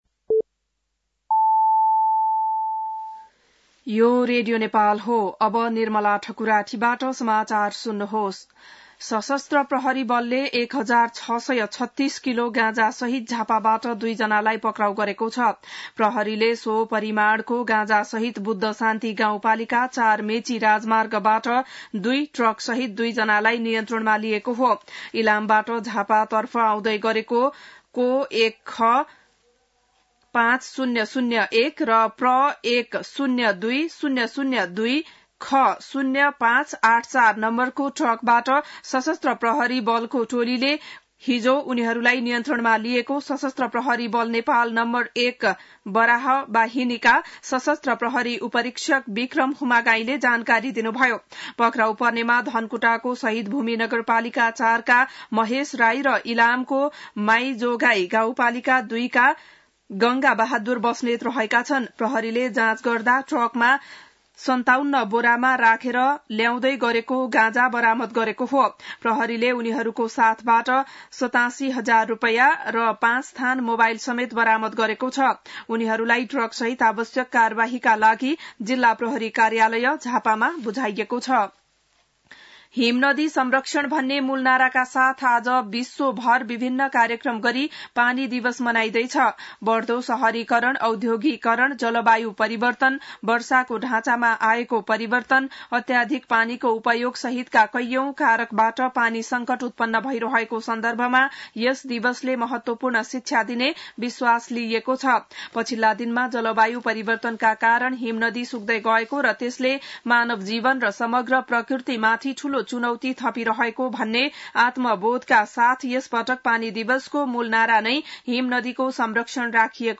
बिहान ११ बजेको नेपाली समाचार : ९ चैत , २०८१
nepali-news-11.mp3